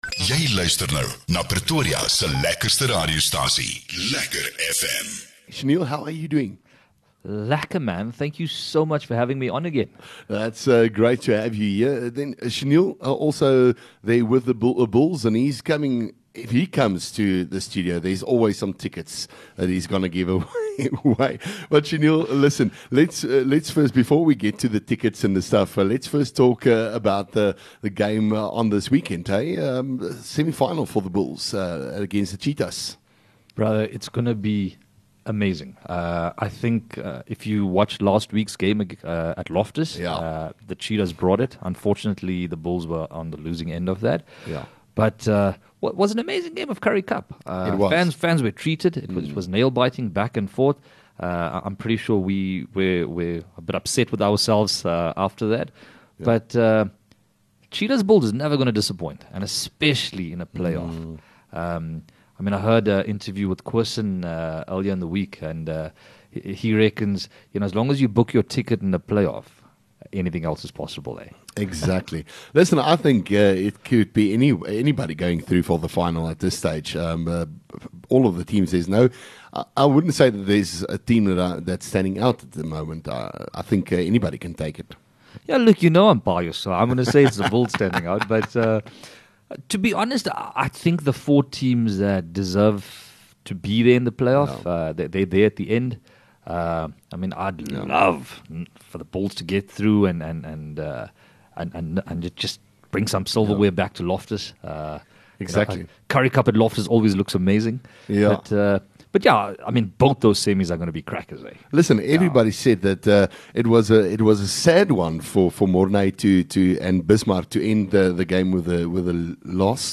LEKKER FM | Onderhoude 14 Jun Vodacom Bulls